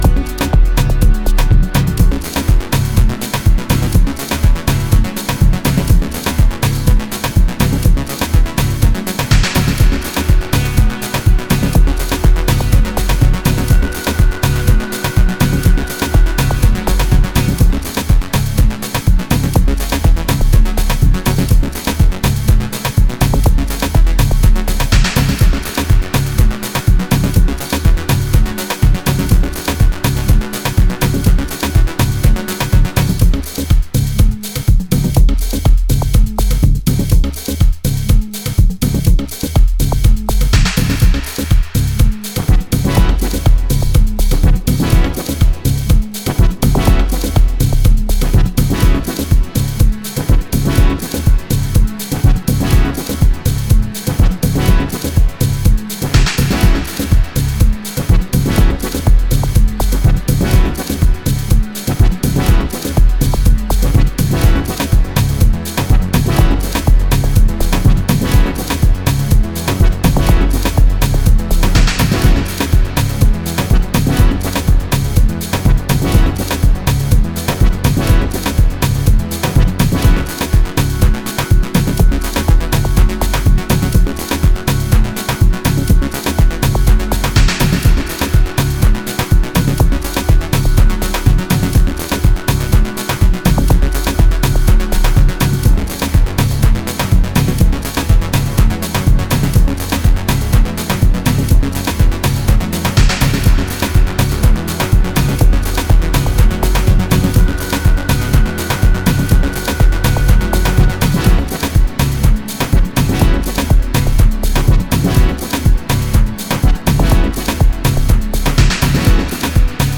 in its full vibrating and hipnotising 11 minutes